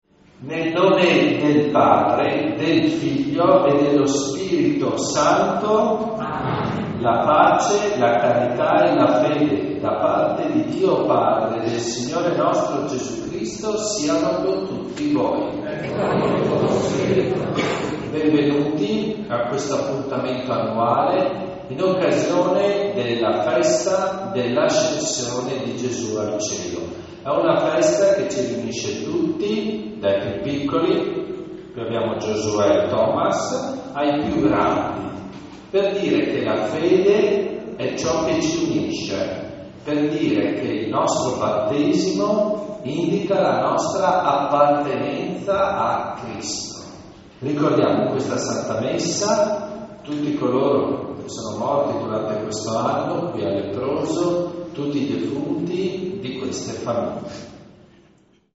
Leproso di Premariacco (UD), 1 Giugno 2025
Festa dell'Ascensione
CAMPANE